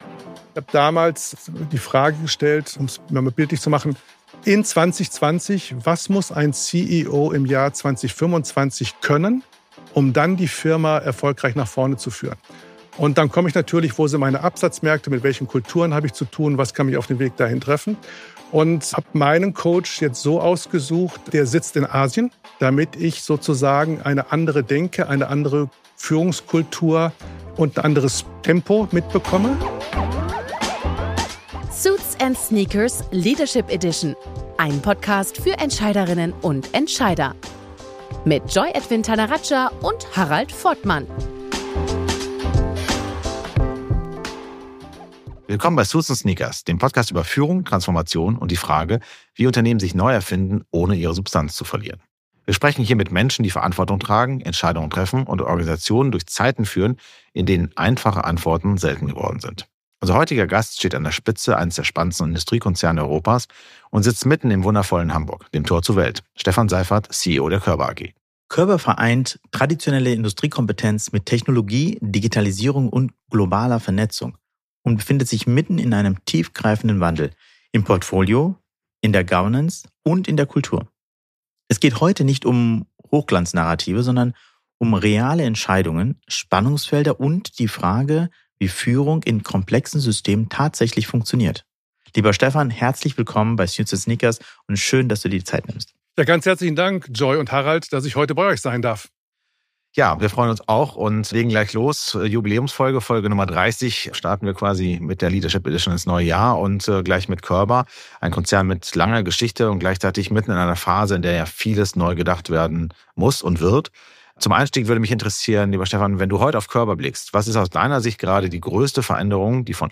Im Gespräch teilt er seine Erfahrungen und Einschätzungen zu den Herausforderungen und Chancen der digitalen Transformation sowie zur notwendigen Anpassungsfähigkeit in zunehmend dynamischen Märkten. Im Mittelpunkt stehen dabei die Rolle von Kommunikation und Coaching ebenso wie die Verantwortung von Führung gegenüber den Mitarbeitenden in einem sich wandelnden Arbeitsumfeld.